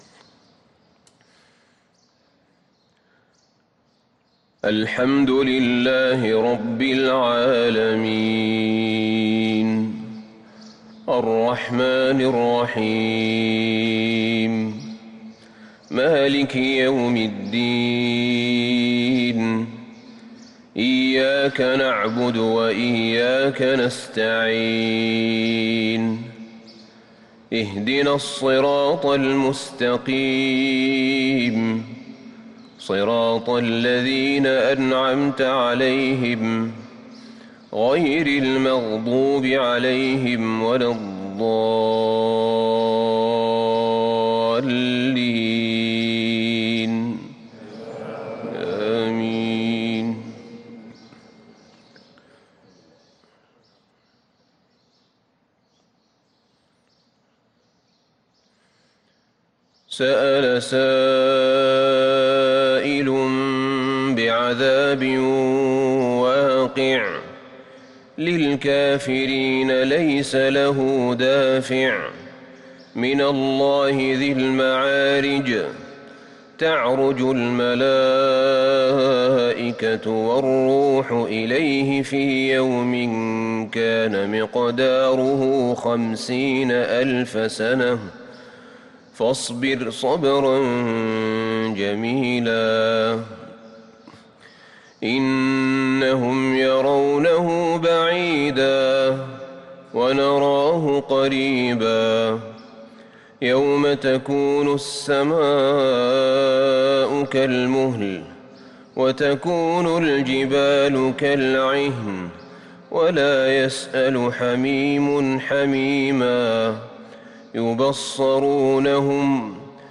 صلاة الفجر للقارئ أحمد بن طالب حميد 15 ربيع الآخر 1443 هـ
تِلَاوَات الْحَرَمَيْن .